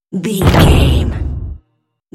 Cinematic drum hit trailer
Sound Effects
Epic / Action
In-crescendo
Atonal
heavy
intense
dark
aggressive
hits